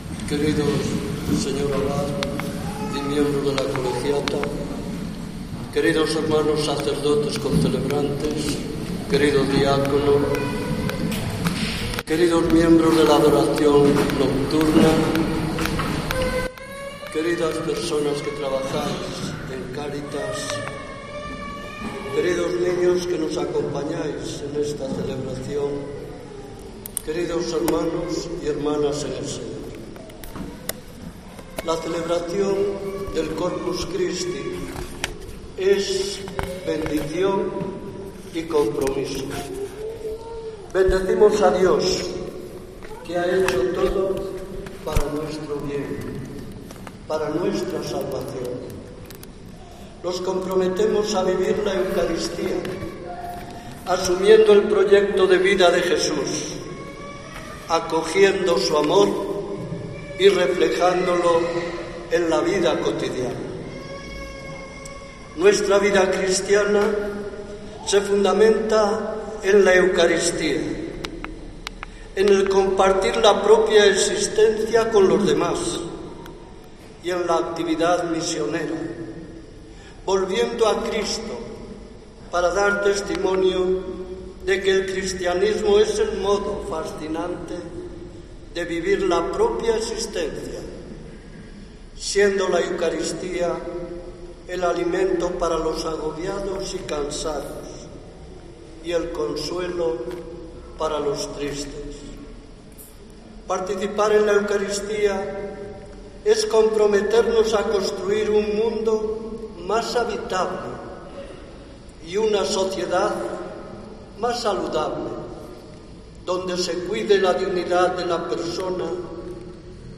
Homilía Julián Barrio